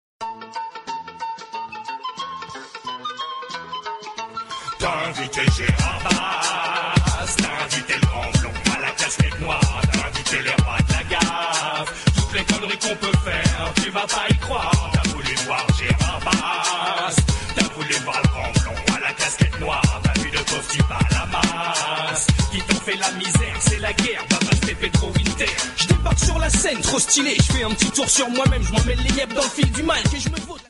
Samples Hiphop